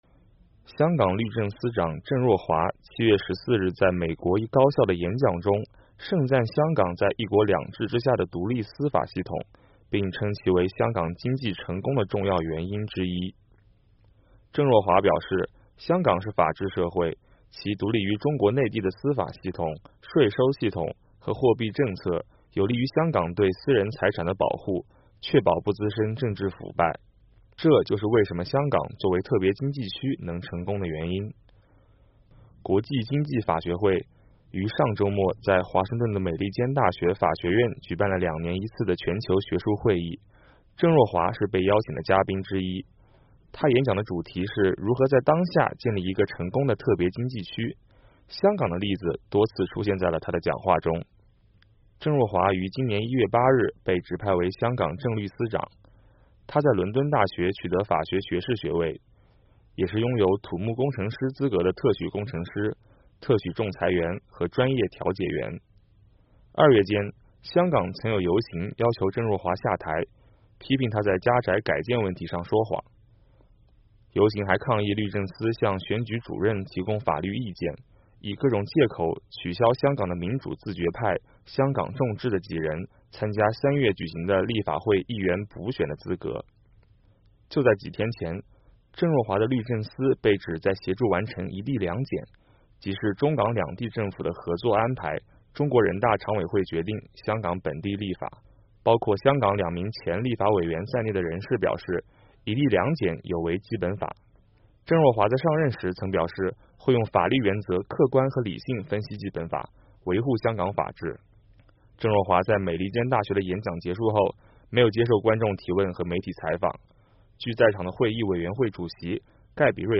香港律政司长郑若骅7月14日在美国一高校的演讲中盛赞香港在一国两制之下的独立司法系统，称其为香港经济成功的重要原因之一。